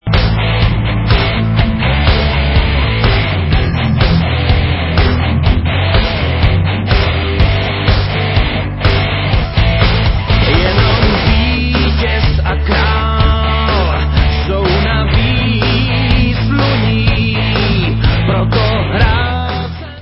vocals, guitars
drums, vocals
keyboards
bass